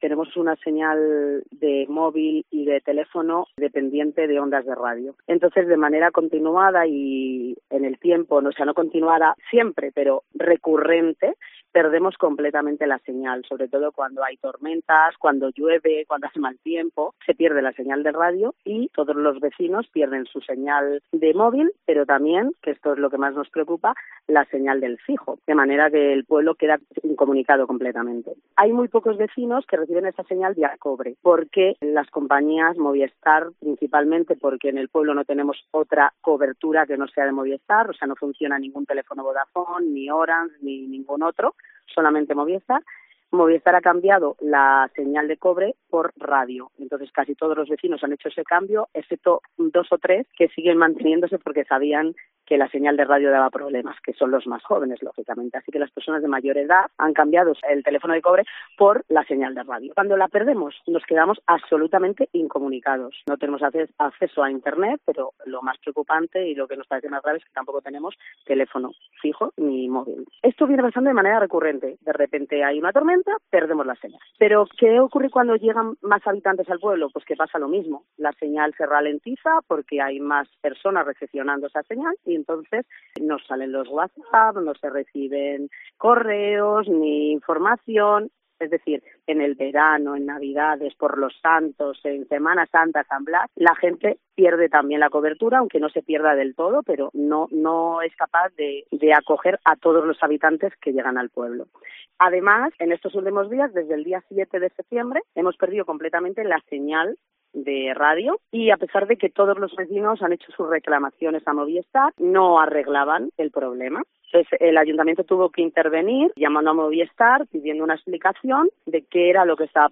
Es la denuncia de Susana Moya, teniente de alcalde en el Ayuntamiento de Monelón, quien ha explicado en declaraciones a COPE Salamanca que cuando aumenta la población, que habitualmente es de unos 100 habitantes, la señal es insuficiente para cubrir la demanda.